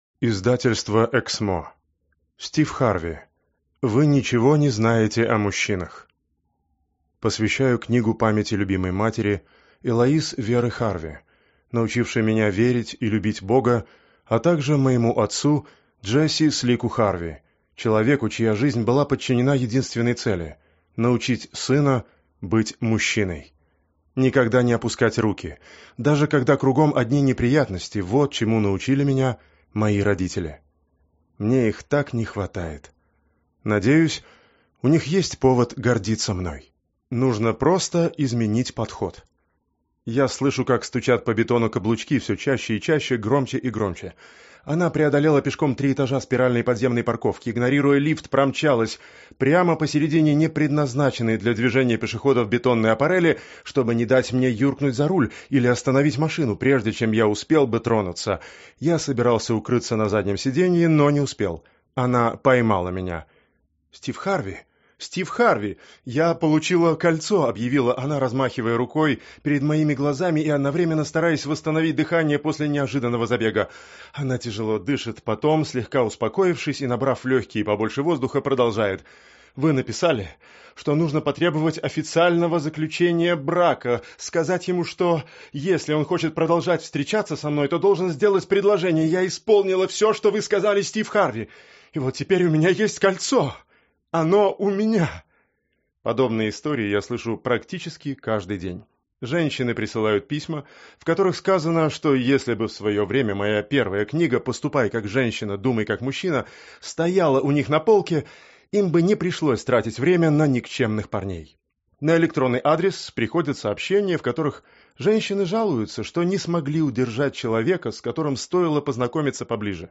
Аудиокнига Вы ничего не знаете о мужчинах - купить, скачать и слушать онлайн | КнигоПоиск